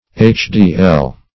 HDL \HDL\ ([=a]ch`d[=e]*[e^]l"), n.